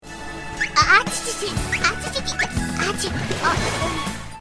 Maki-chan yang kakinya kepanasan saat menginjak pasir pantai
maki-achichichi.mp3